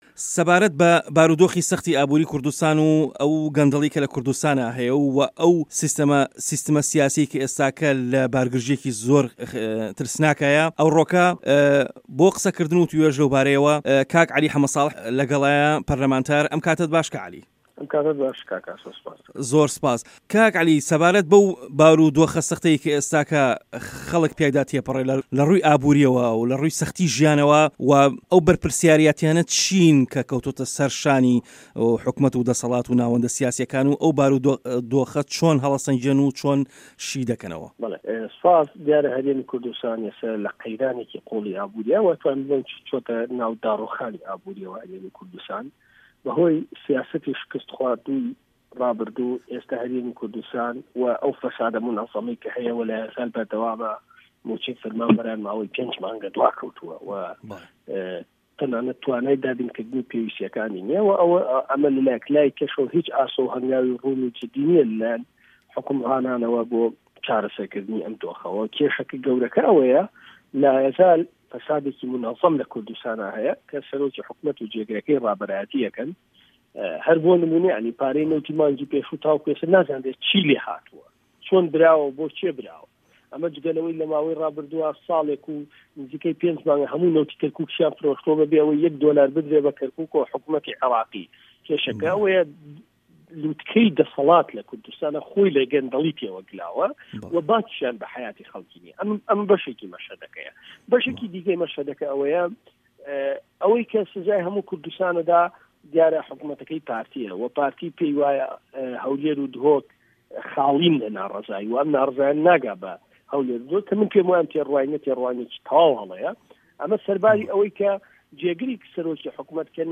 وتووێژ لەگەڵ عەلی حەمە سـاڵح